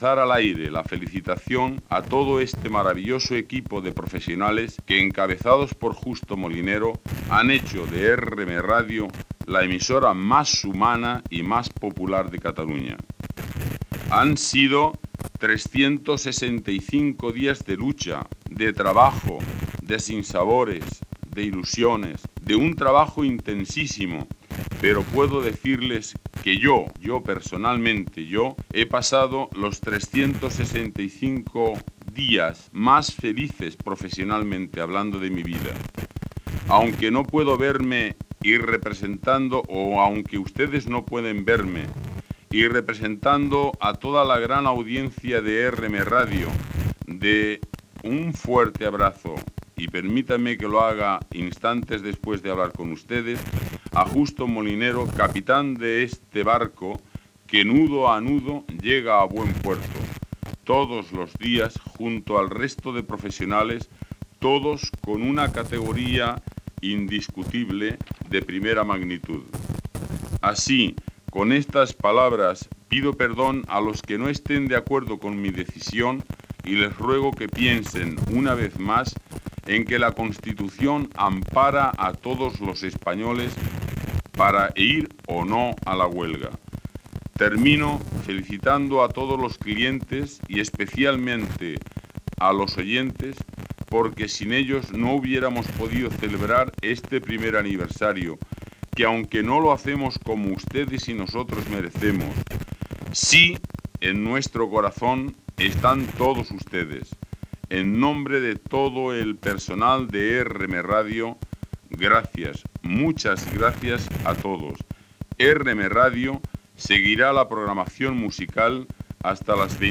Adhesió a la Vaga General i indicatiu.
FM